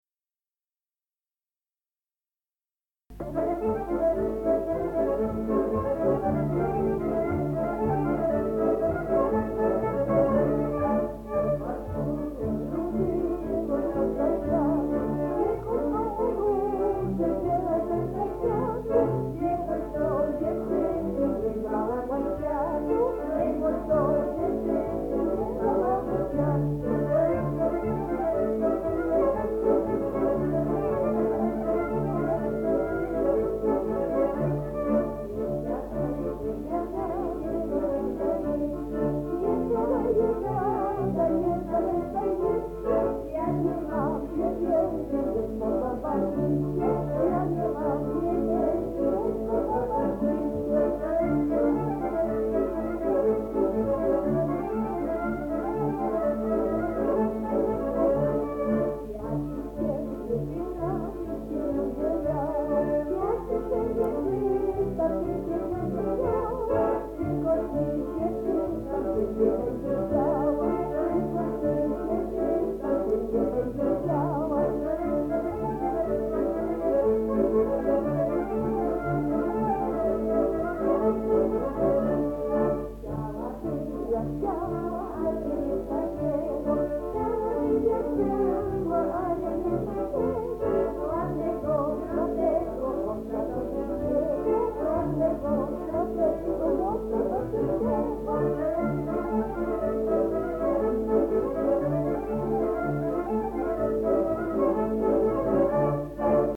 Nie chodź koło róży – Żeńska Kapela Ludowa Zagłębianki
Nagranie archiwalne